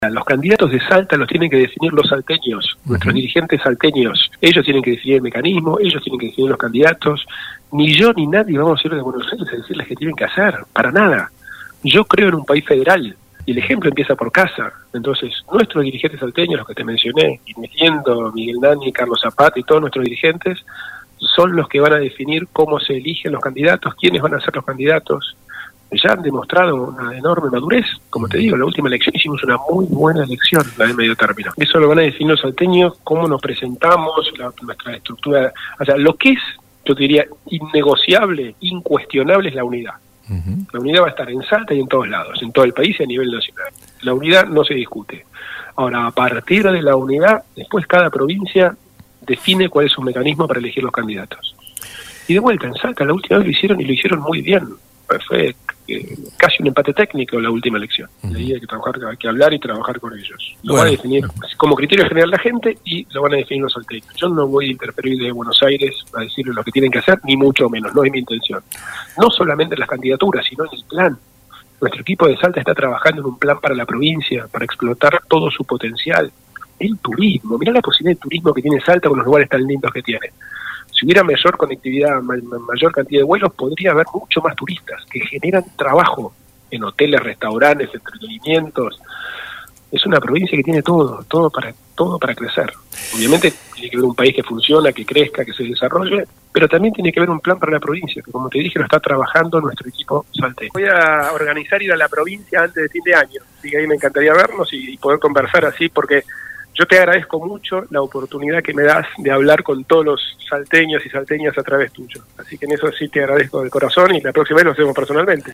SALTA (Redacción) – Horacio Rodríguez Larreta brindó una entrevista para un medio radial de la provincia.